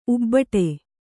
♪ ubbaṭe